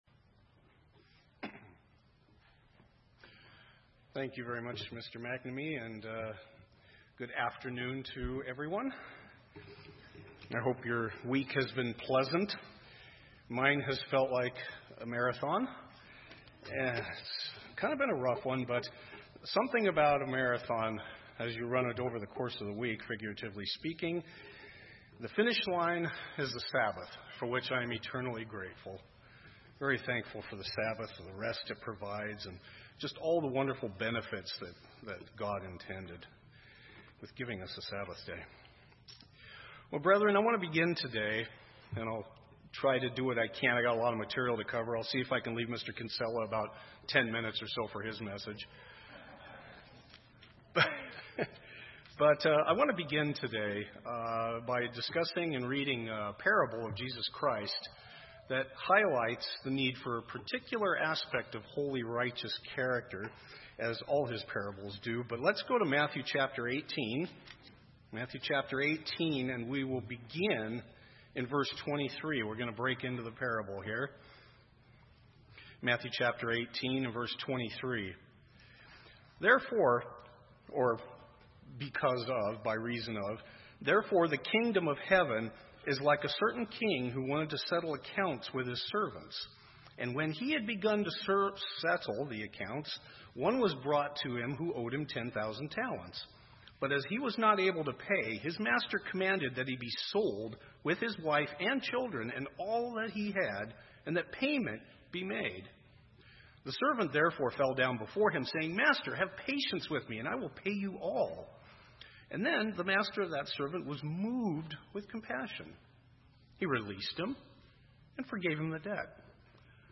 Sermons
Given in Salem, OR